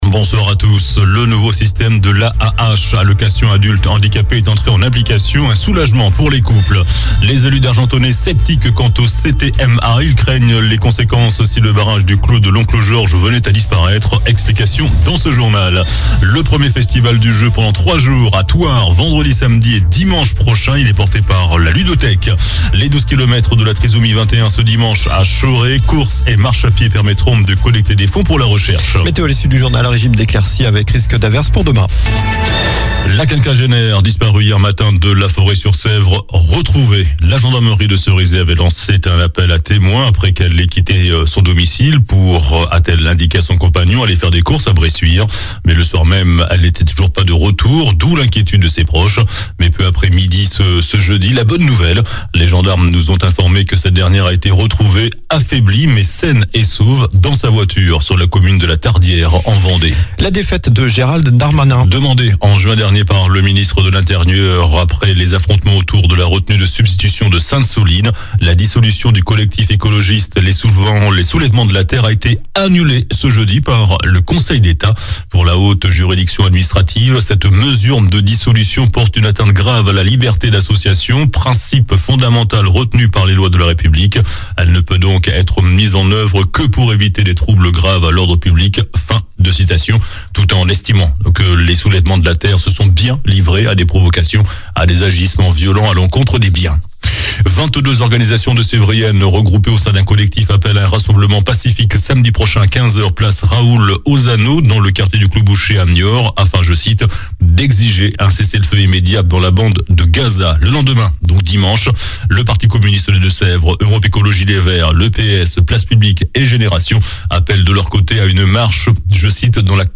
JOURNAL DU JEUDI 09 NOVEMBRE ( SOIR )